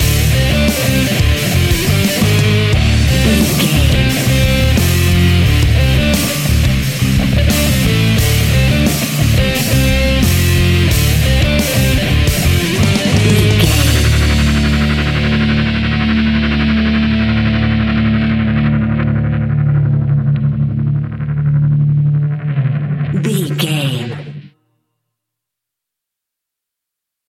Epic / Action
Fast paced
Aeolian/Minor
hard rock
heavy metal
blues rock
distortion
instrumentals
rock guitars
Rock Bass
Rock Drums
heavy drums
distorted guitars
hammond organ